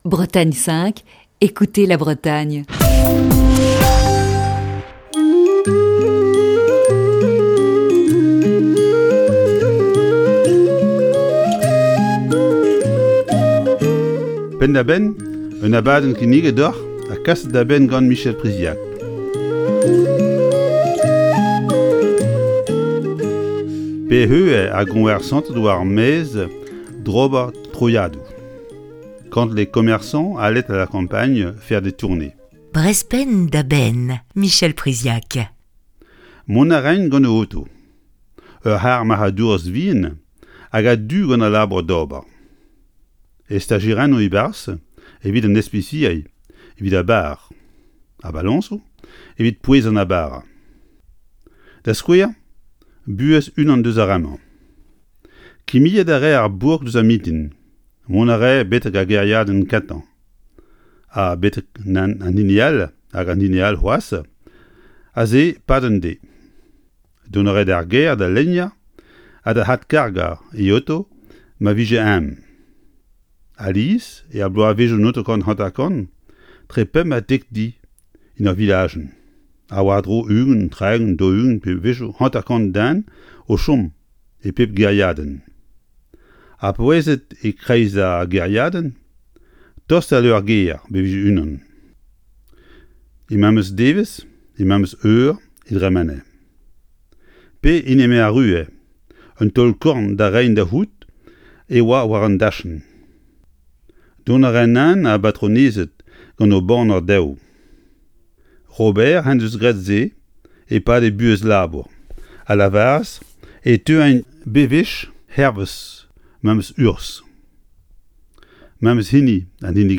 (Chronique diffusée le 21 septembre 2020).